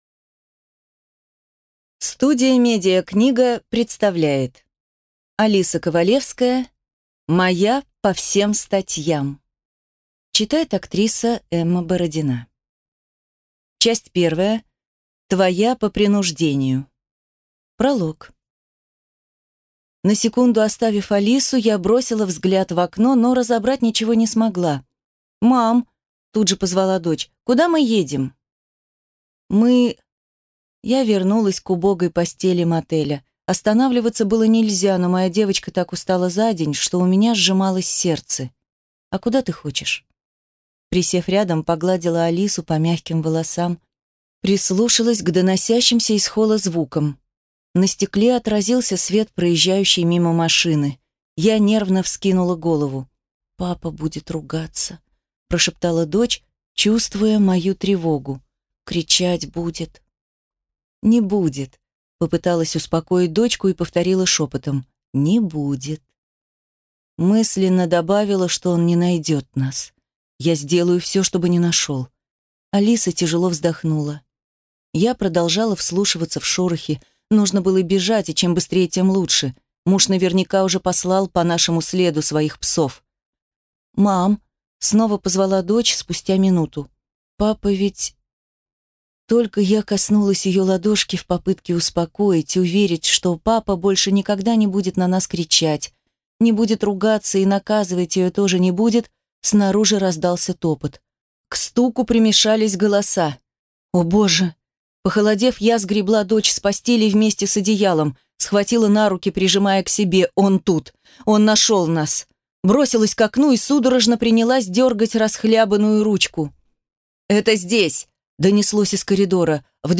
Аудиокнига Твоя по принуждению | Библиотека аудиокниг